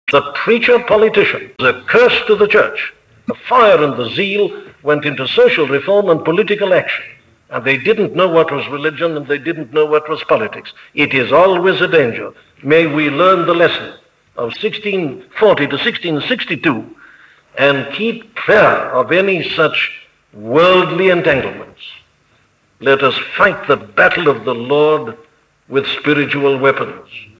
(He delivered this lecture on Boxing Day, 1962).